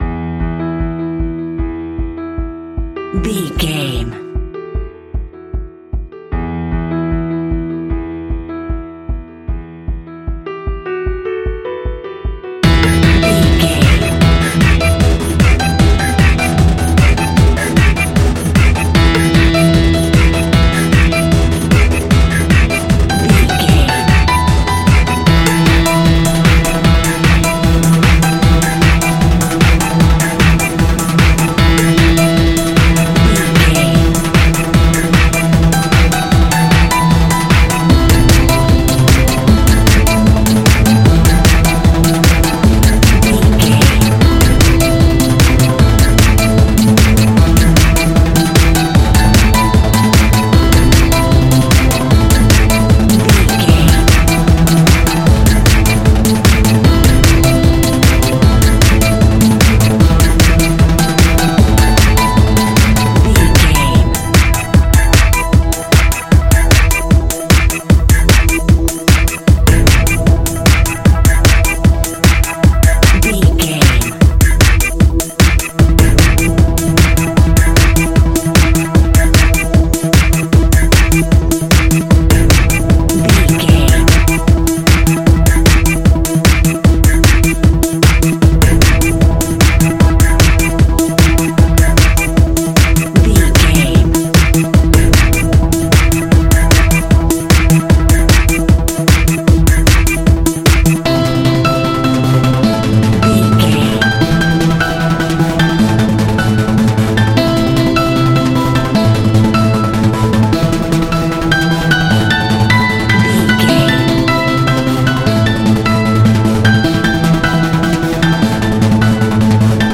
Music for a Trance Club Full Mix.
Aeolian/Minor
Fast
meditative
futuristic
hypnotic
industrial
piano
drum machine
synthesiser
acid house
hardcore trance
tech house
uptempo
synth leads
synth bass